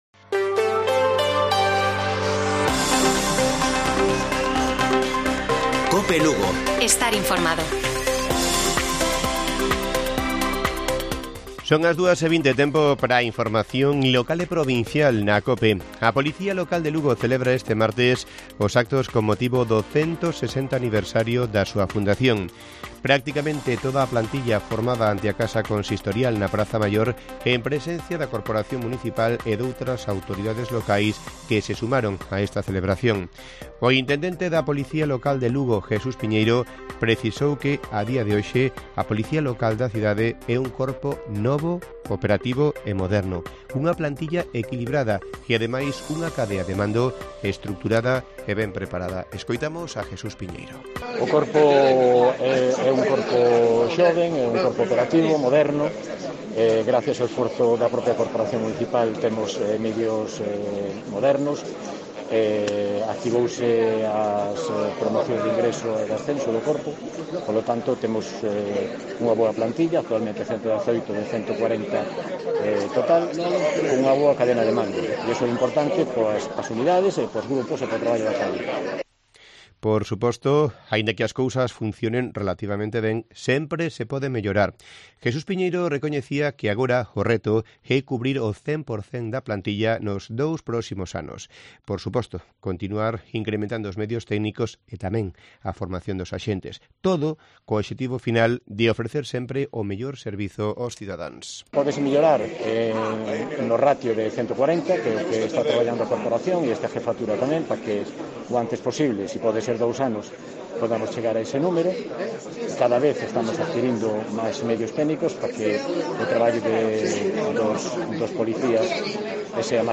Informativo Mediodía de Cope Lugo. 24 DE MAYO. 14:20 horas